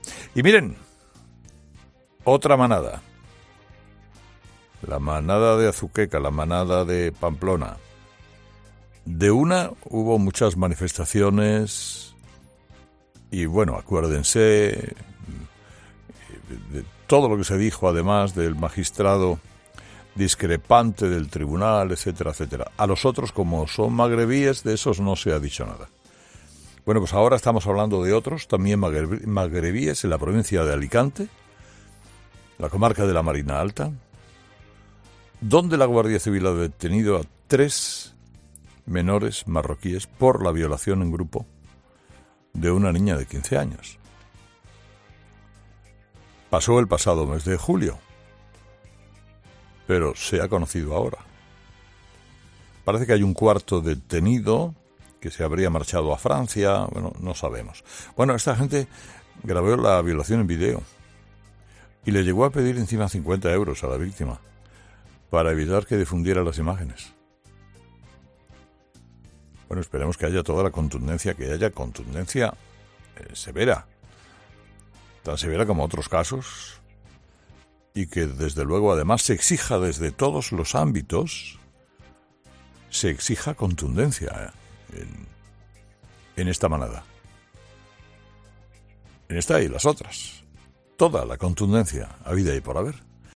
Carlos Herrera, en su editorial de las 6.30 horas de esta mañana, rememora que “hubo muchas manifestaciones” respecto a otras 'manadas'.